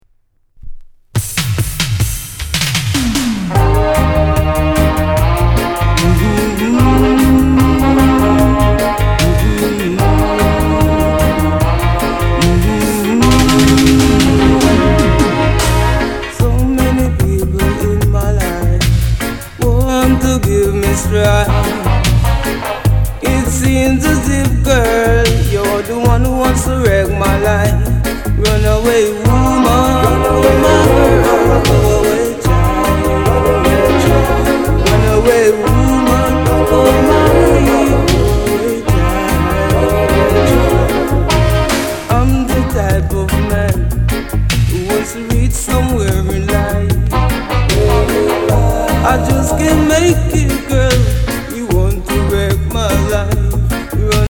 LOVER ROCK